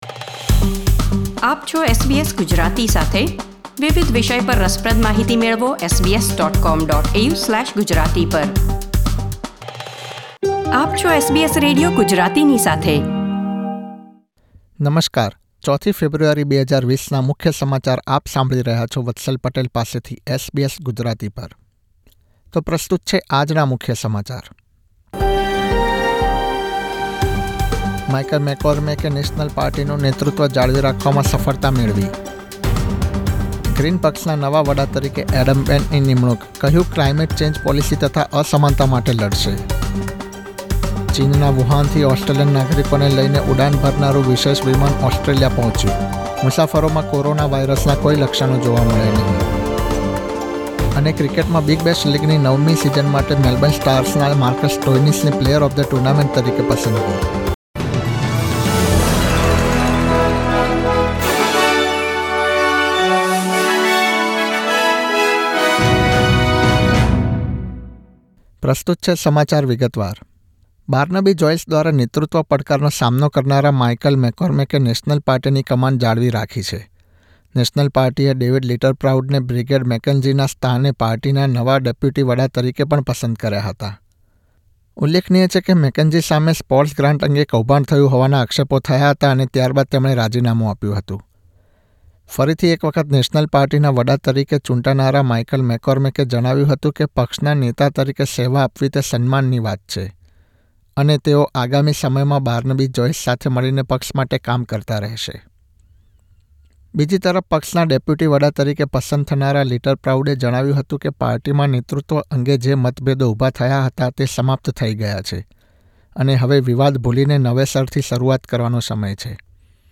SBS Gujarati News Bulletin 4 February 2020